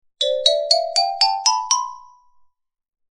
Cartoon-upward-steps-sound-effect.mp3